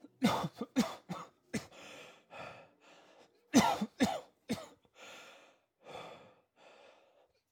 Human, Cough, Male, Breathtaking, Heavy Breathing, Tired SND87932.wav